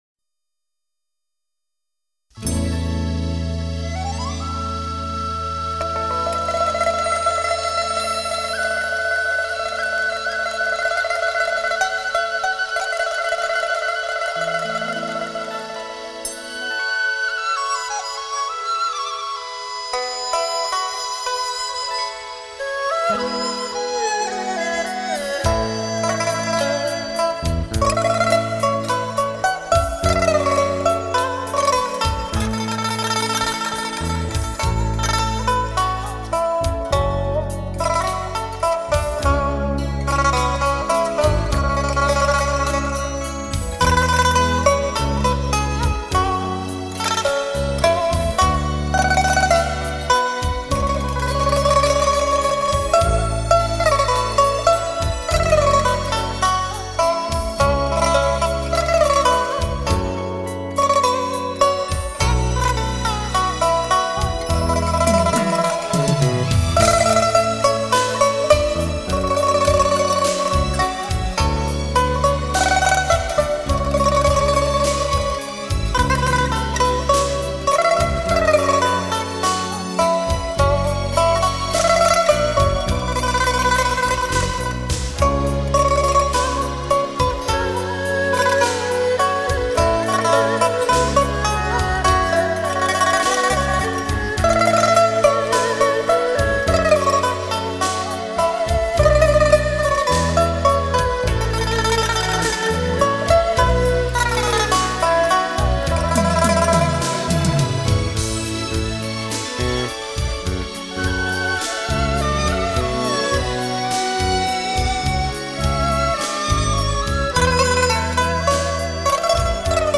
淡雅而纯净的音乐，引发人从宁静中轻轻飘落，随 着和谐的旋律，产生悠远的遐想和启示。